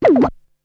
Beatbox 6.wav